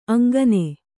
♪ aŋgane